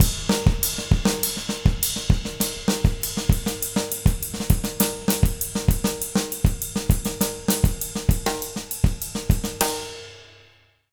100SONGO05-L.wav